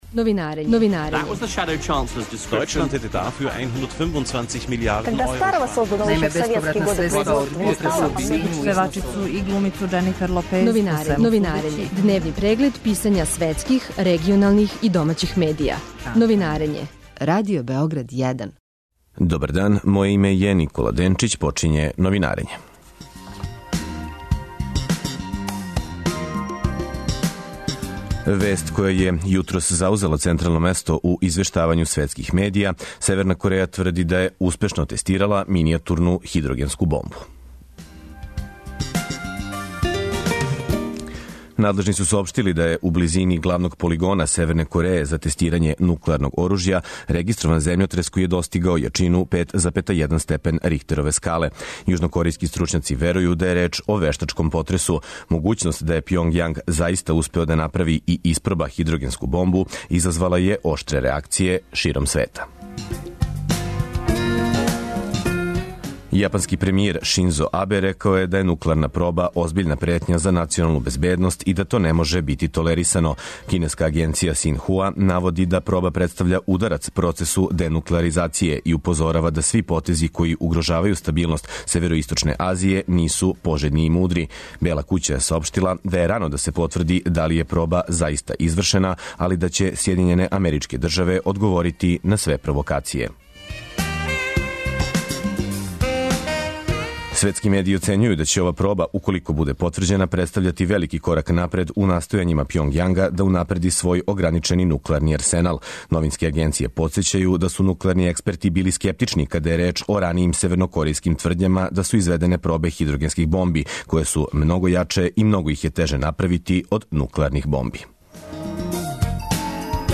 Новинарење | Радио Београд 1 | РТС